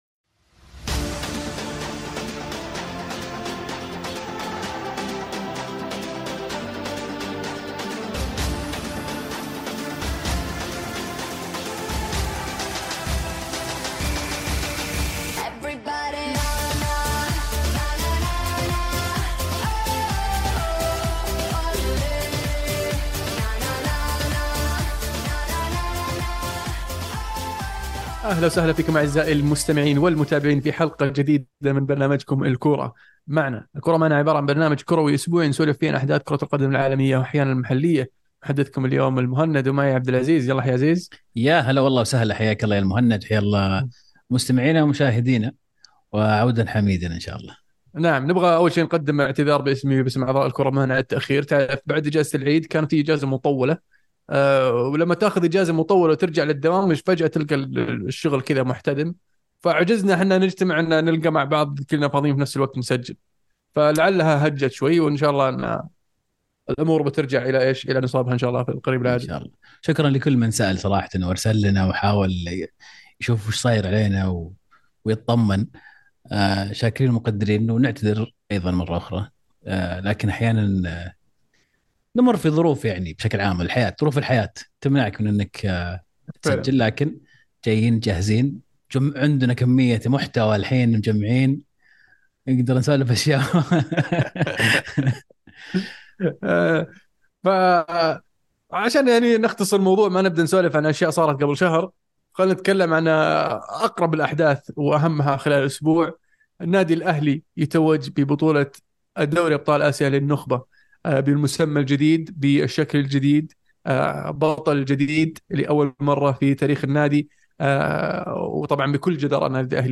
بودكاست "الكورة معنا" برنامج صوتي كروي اسبوعي من تقديم شباب عاشقين لكرة القدم، يناقشون فيه اهم الاحداث الكروية العالمية والمحلية خلال الأسبوع بعيد عن الرسمية.